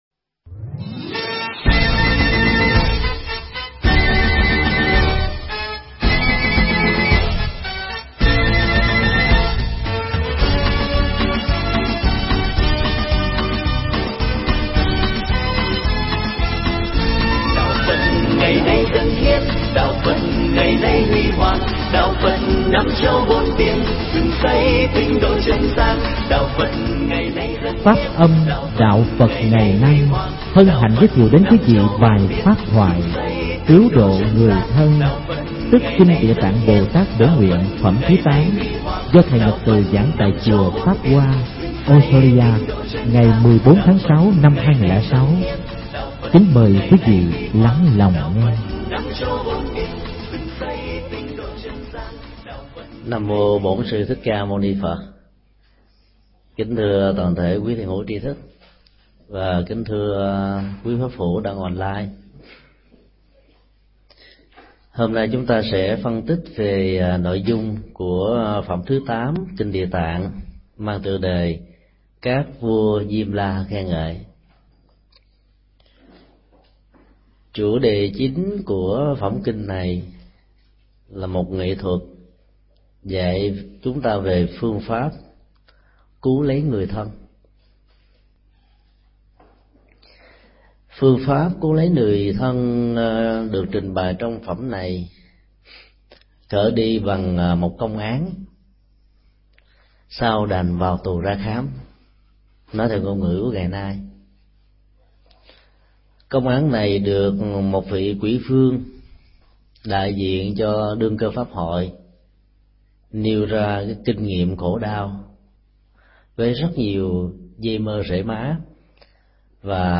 Tại Chùa Pháp Hoa – Australia, ngày 14 tháng 06 năm 2006 thầy Thích Nhật Từ đã chia sẻ pháp thoại Cứu Độ Người Thân – Phần 1/2